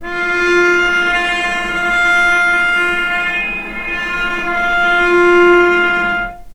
vc_sp-F4-mf.AIF